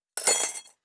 SFX_Metal Sounds_09.wav